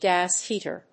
音節gás hèater